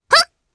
Viska-Vox_Jump_jp.wav